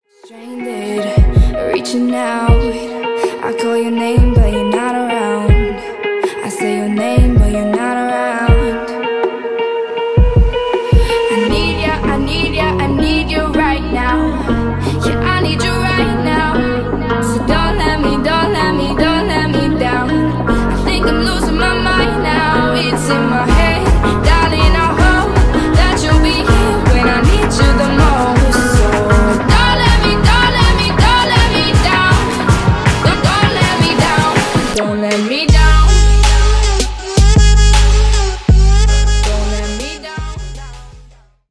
Поп Музыка
спокойные # тихие